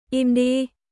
Im đi!イム ディ！静かにして！（強め・カジュアル）